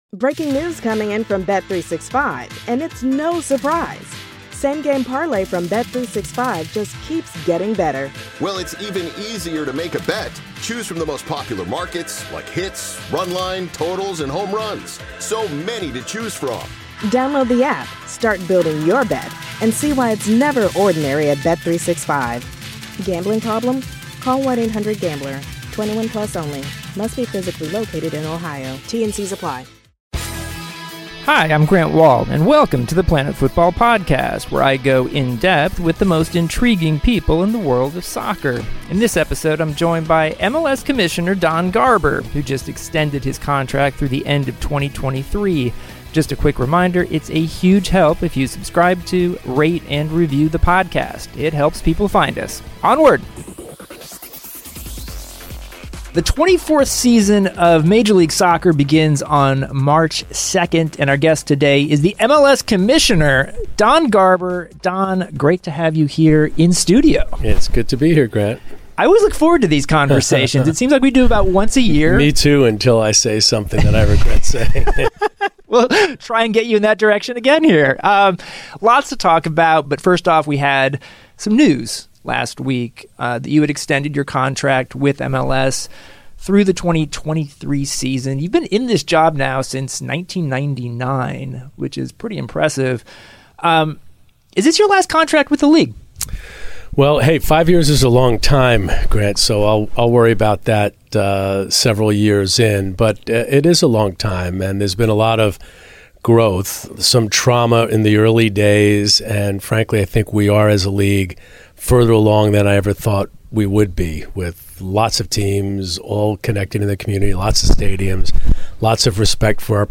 A conversation with MLS commissioner Don Garber